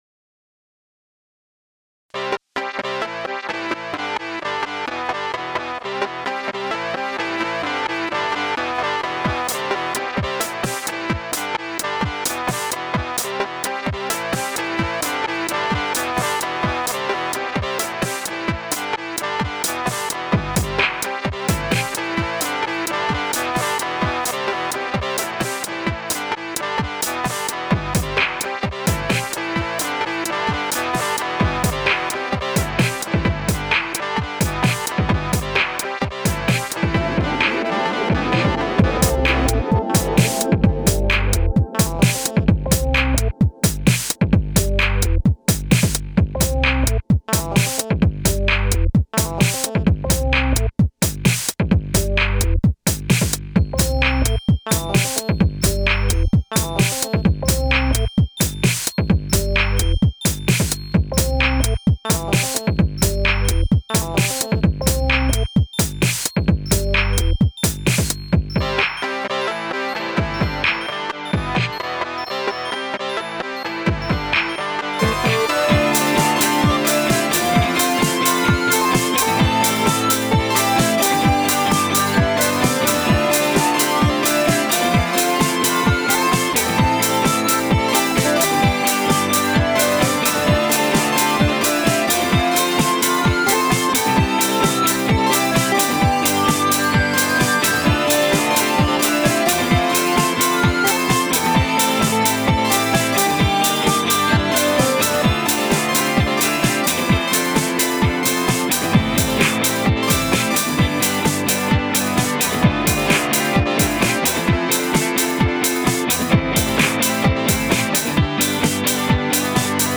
The harpsichord-ish synth line at the end is supposed to be "ba"s, "doo"s, or somesuch.
The only "lock" part is the "chorus", which is the delay'd repeating synthy thing. The chords over that are Dm, F, Am (replaceable with Cmaj for notably triumphant lines), G. I'm not sure I really like the other two attempted parts, though - so any help with padding that chorus-y part into a real song would be appreciated.
man, this chorus is hot! i dig the outro section, as well. i dig the organ-y voice that's doing something like C - A A - G. the thing i like most about it is something i was always drawn to in paraleisure stuff, namely, the unique sound you get with the combination of awesome rythm guitar sounds and beats.